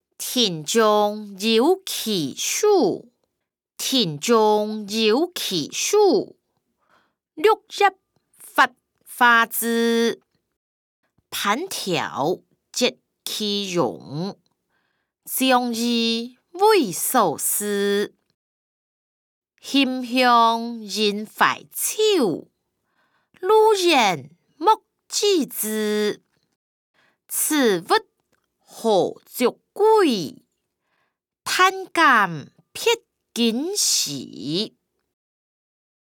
古典詩-庭中有奇樹音檔(大埔腔)